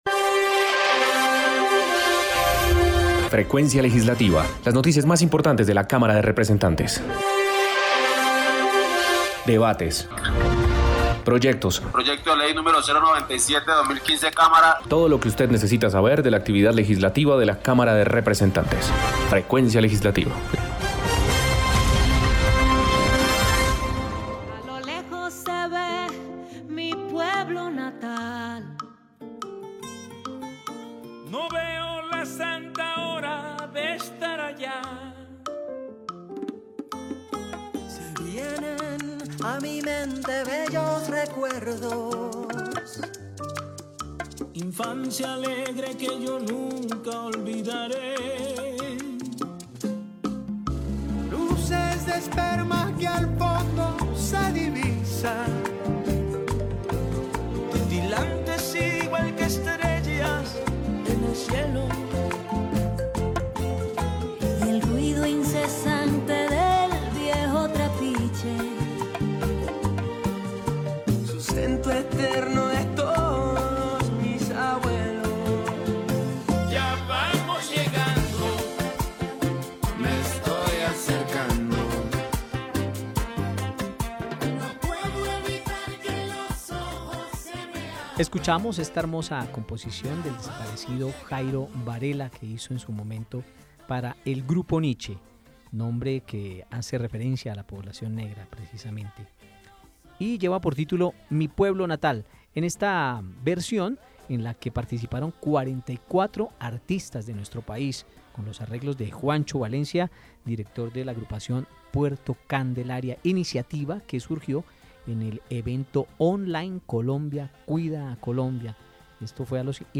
Programa radial Frecuencia Legislativa Sábado 25 de julio 2020